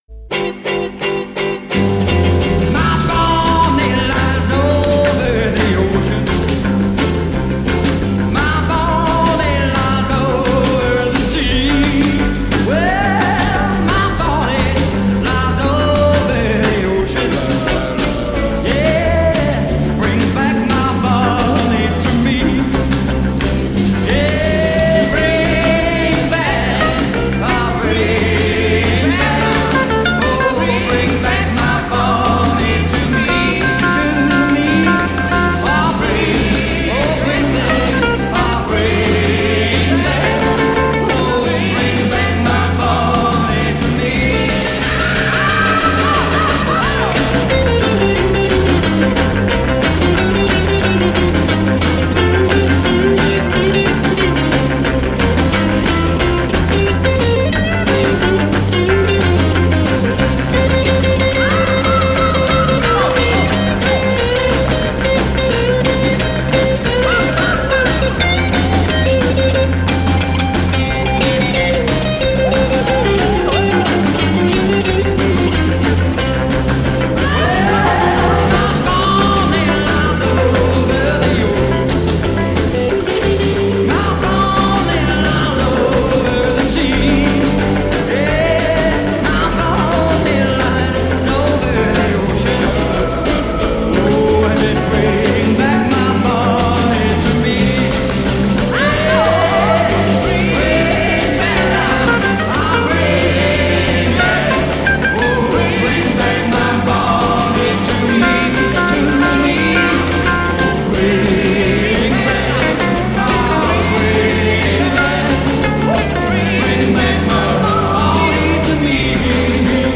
рок-н-ролльная обработка